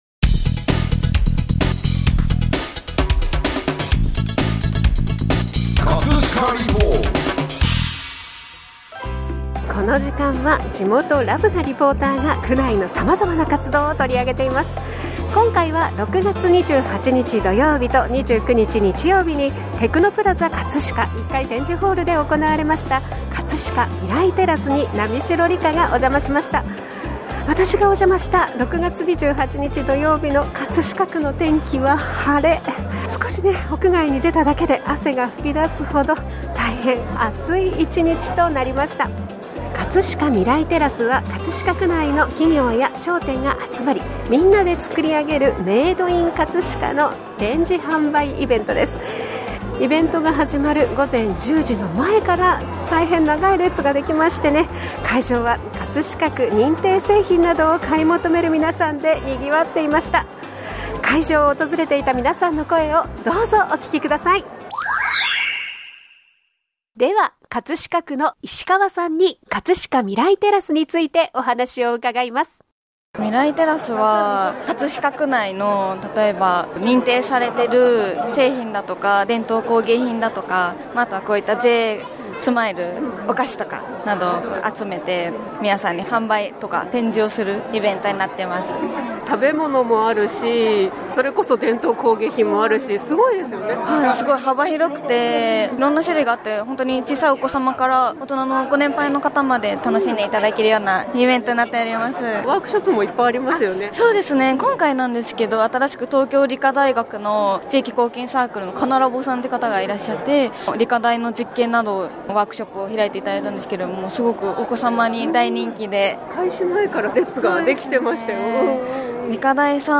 【葛飾リポート】 葛飾リポートでは、区内の様々な活動を取り上げています。
会場を訪れていた皆さんの声をどうぞお聴きください。